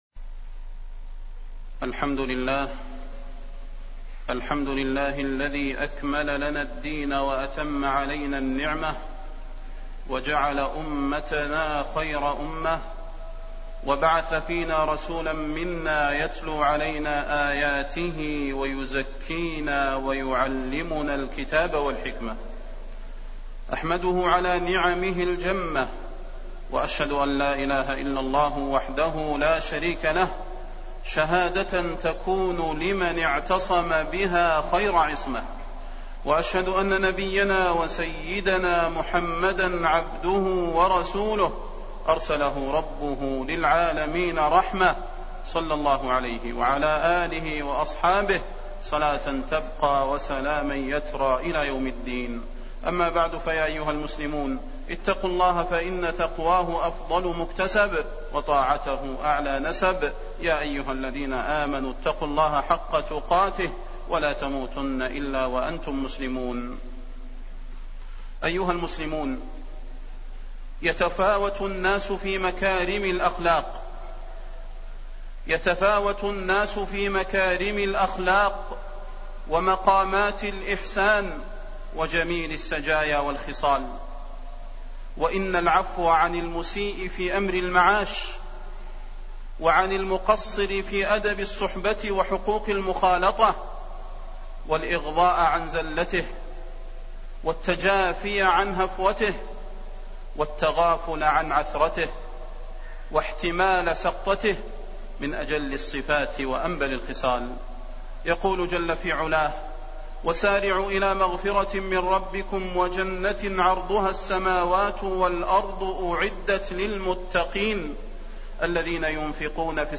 فضيلة الشيخ د. صلاح بن محمد البدير
تاريخ النشر ٣٠ ربيع الأول ١٤٣٠ هـ المكان: المسجد النبوي الشيخ: فضيلة الشيخ د. صلاح بن محمد البدير فضيلة الشيخ د. صلاح بن محمد البدير العفو والصفح